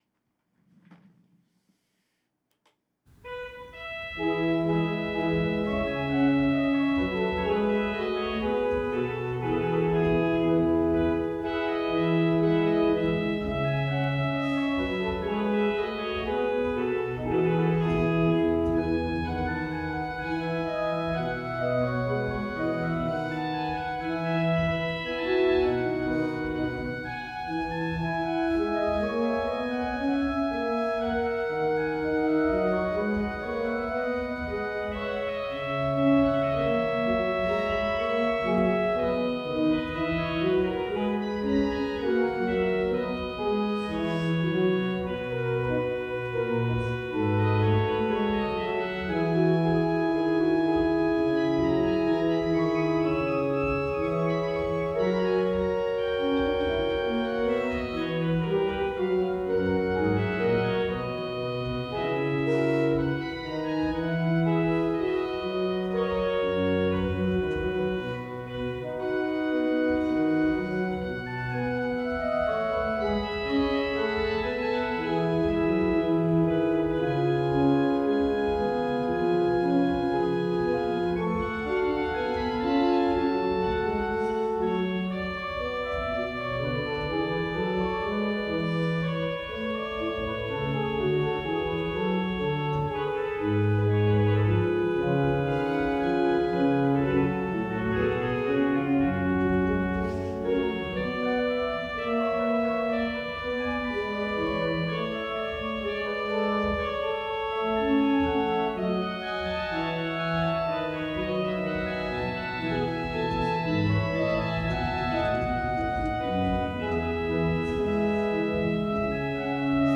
I’d try to turn the concluding movement of the E Major Flute Sonata into a kind of trumpet concerto.